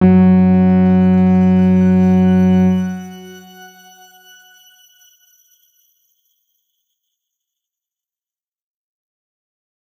X_Grain-F#2-mf.wav